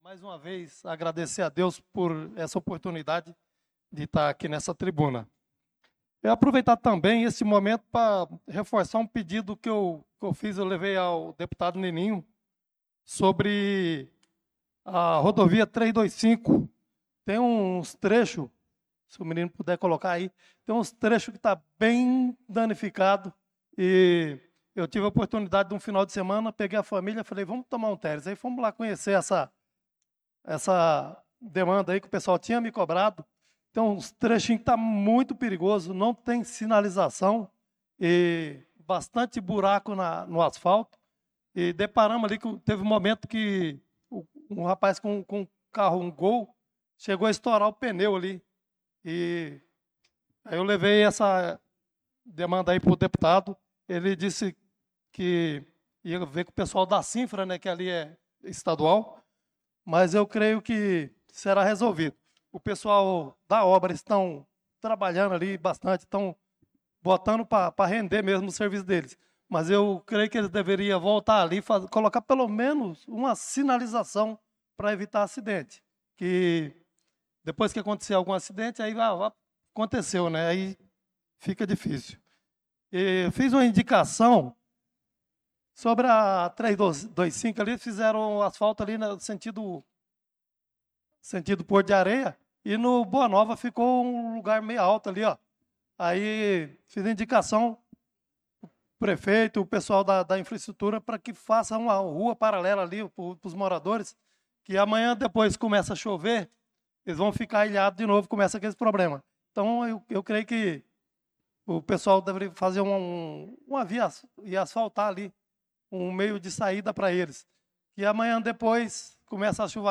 Pronunciamento do vereador Chicão Motocross na Sessão Ordinária do dia 04/08/2025.